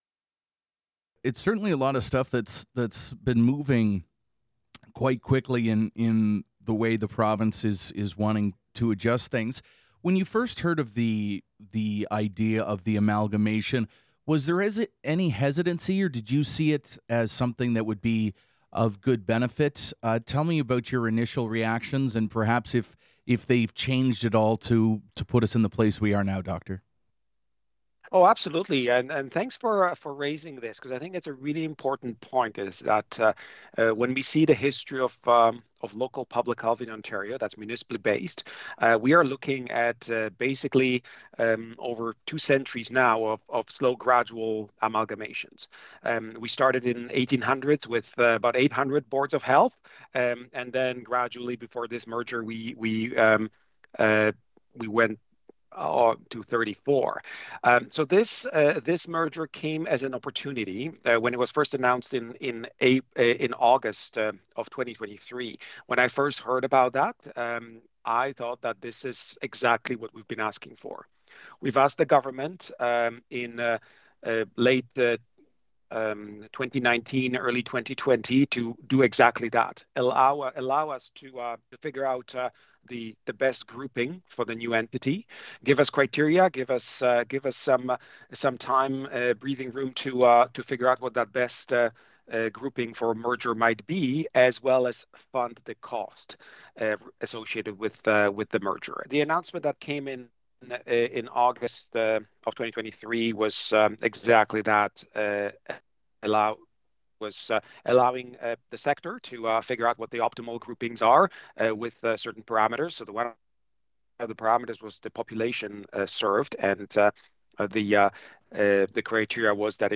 Quinte News had the chance to speak with the Medical Officer of Health of the South East Health Unit, Dr. Piotr Oglaza, about the recent merger of the three health units and how it will affect residents in Hastings and Prince Edward counties.
dr-oglaza-interview.wav